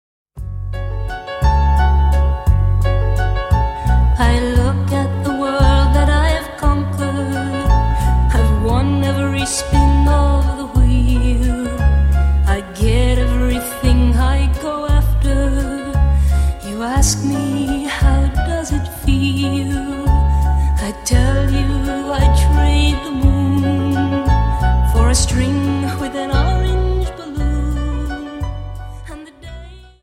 Dance: Viennese Waltz Song
Viennese Waltz 58